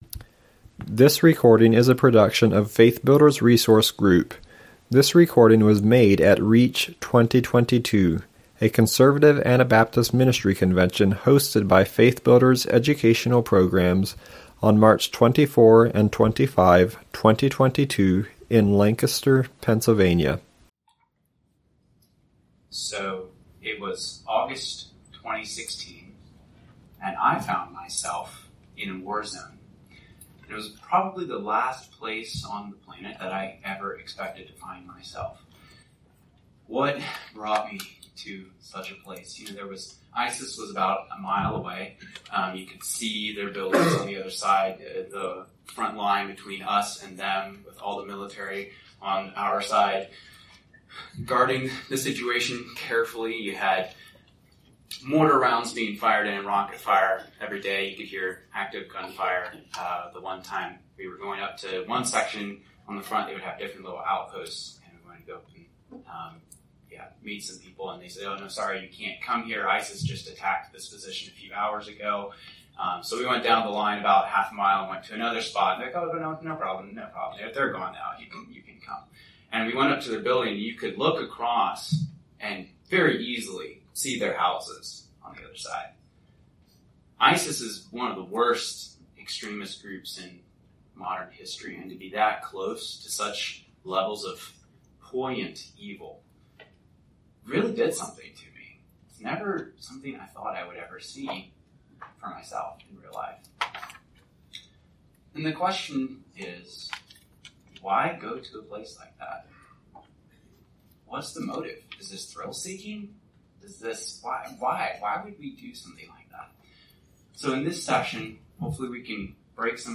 at REACH 2022, on March 24-25, 2022, at Calvary Church, in Lancaster, Pennsylvania.